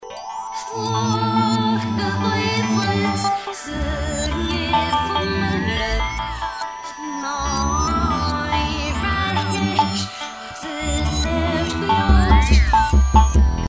It’s pop with a dark side.
The melody, intricate. The production, layered and dense.
in reverse